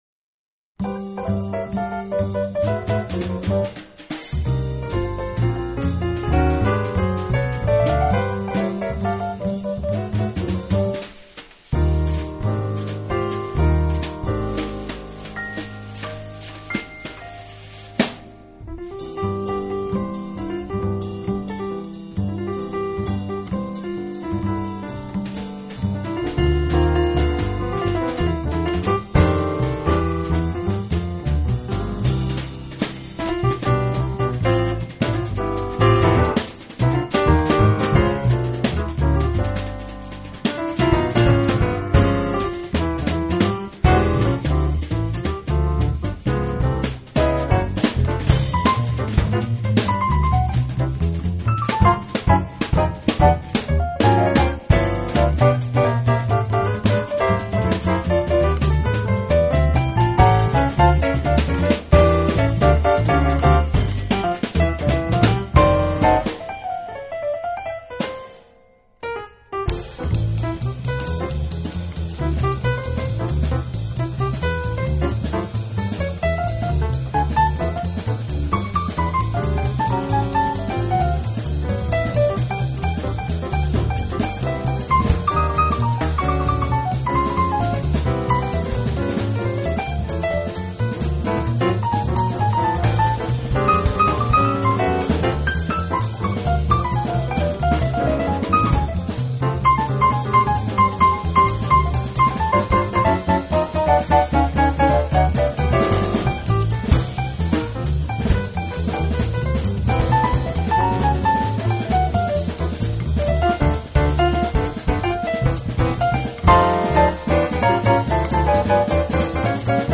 专辑类型：Smooth Jazz
风格流派: Fusion/Modern Jazz